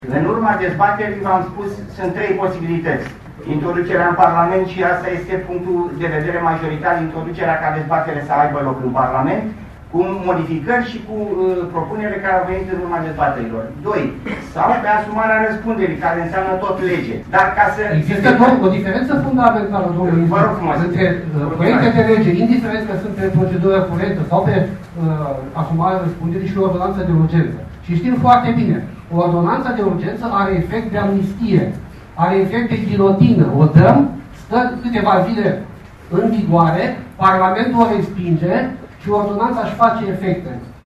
Ministrul Justiției, Florin Iordache, a declarat în timpul dezbarerii organizate pe tema modificării legislației anticorupție și a grațierii că Guvernul ar putea renunța la emiterea unor ordonanțe în favoarea trimiterii proiectelor în Parlament.